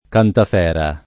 cantafera [ kantaf $ ra ] s. f.